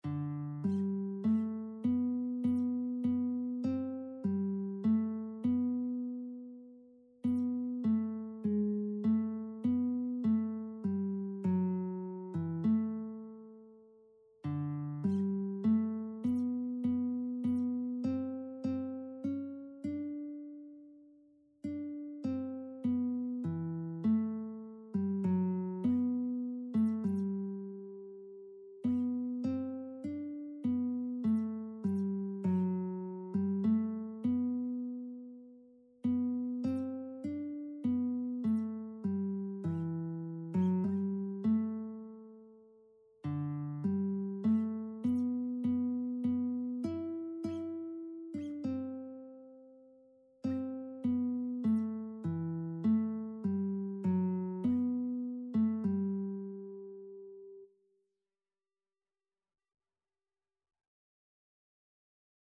Traditional Music of unknown author.
3/4 (View more 3/4 Music)
G major (Sounding Pitch) (View more G major Music for Lead Sheets )
Classical (View more Classical Lead Sheets Music)